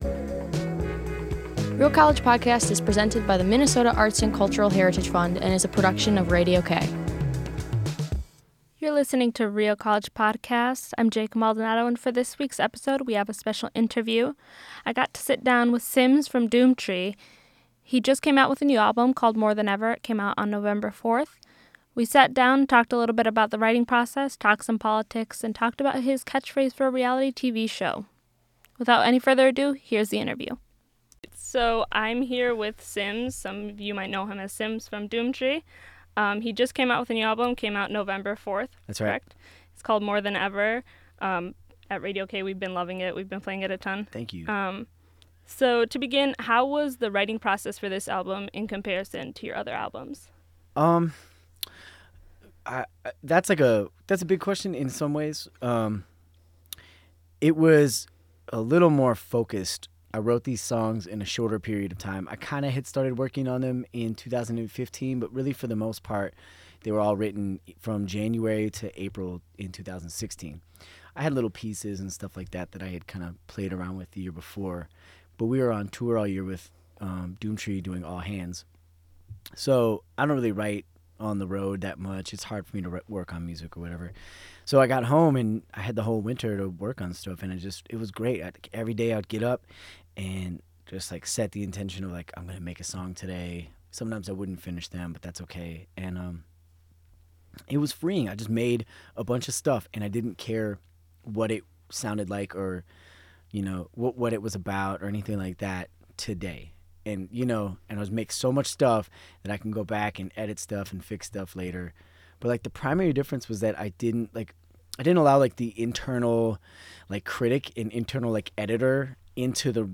Interview: Real College Podcast